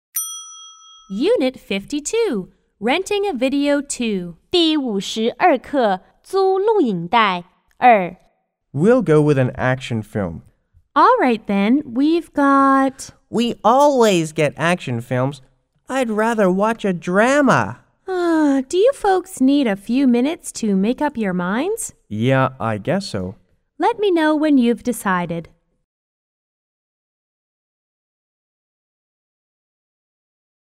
C= Customer 1 S= Salesperson C= Customer 2